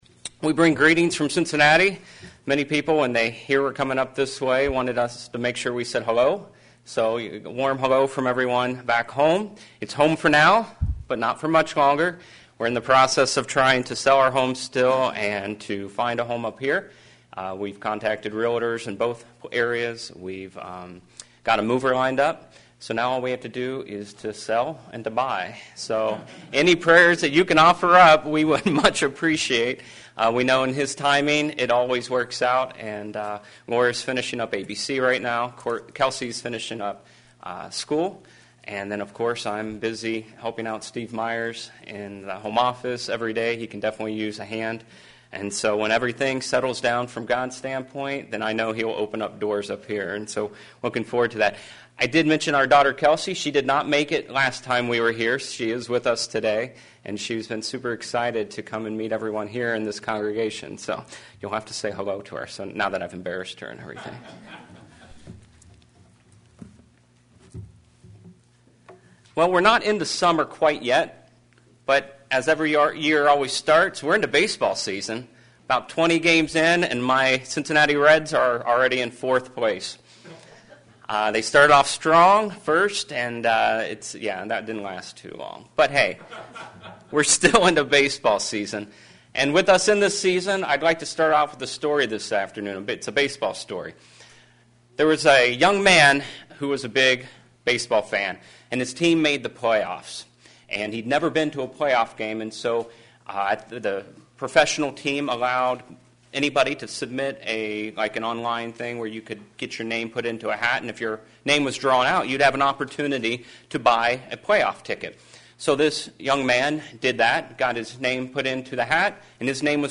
Which nature do we use and which nature ought we to use? sermons Transcript This transcript was generated by AI and may contain errors.